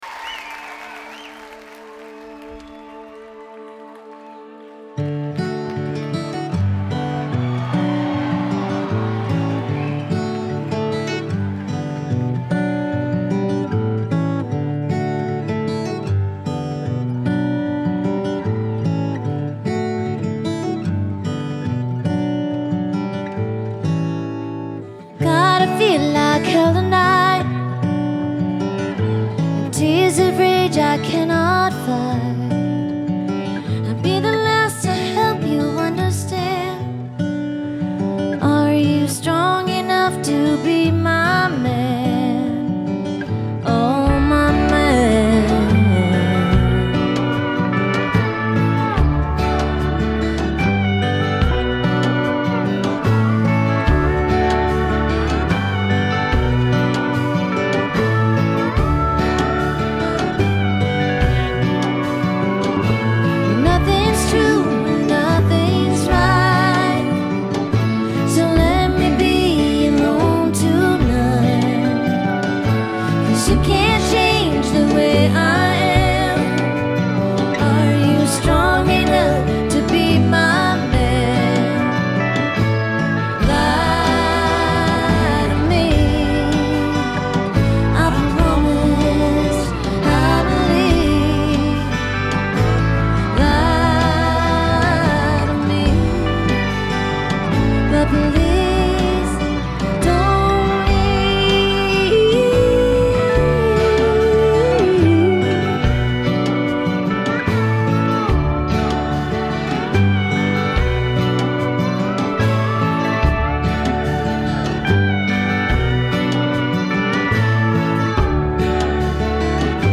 Genre : Pop
Live from the Theatre at Ace Hotel